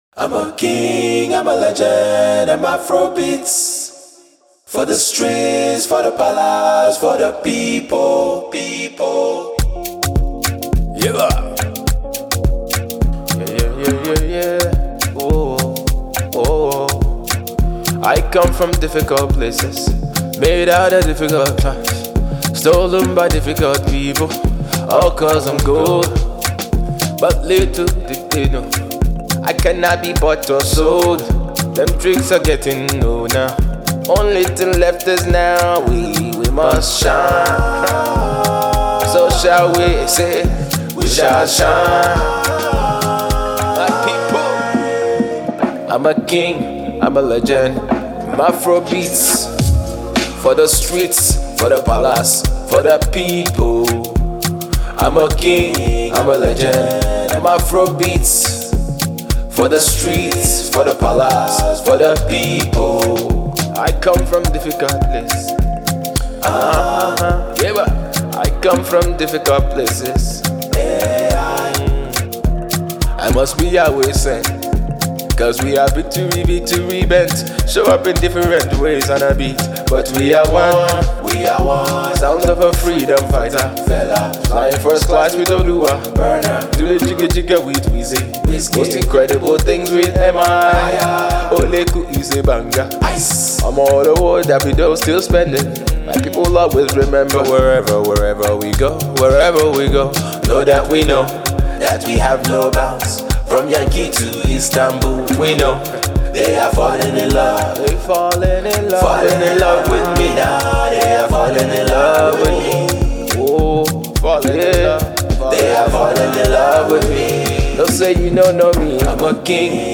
Lyrical + Meaningful + AfroHipHop + Full Of Energy